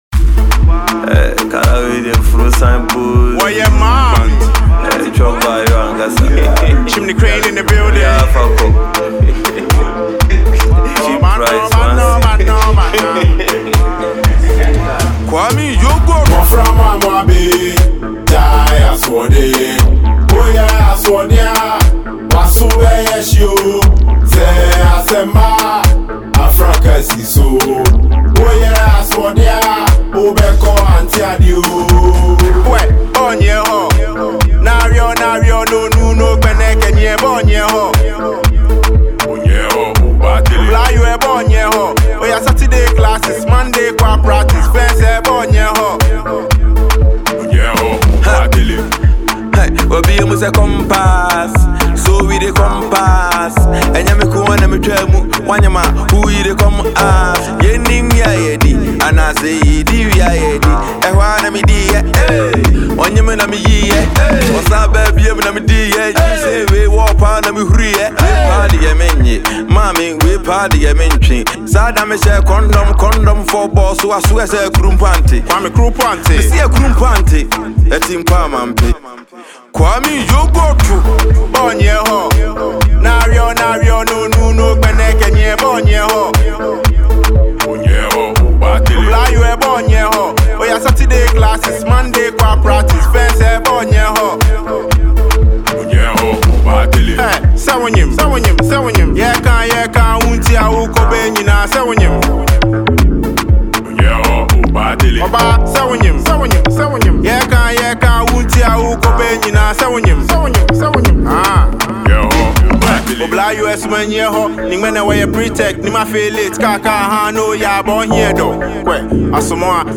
This is a banger all day.